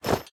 Minecraft Version Minecraft Version 25w18a Latest Release | Latest Snapshot 25w18a / assets / minecraft / sounds / item / armor / equip_diamond5.ogg Compare With Compare With Latest Release | Latest Snapshot
equip_diamond5.ogg